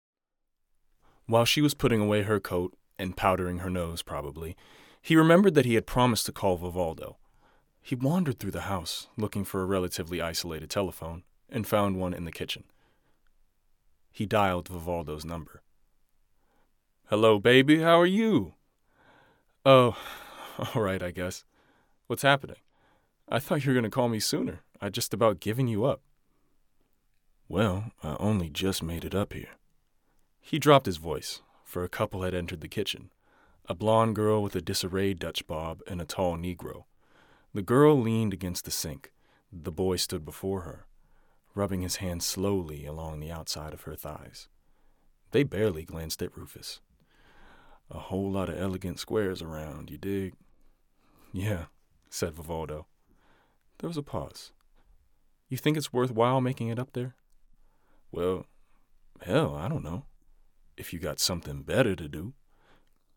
20s-30s. US. A deep, resonant voice with great range. Calm and assured to boisterous and brazen.
Audiobook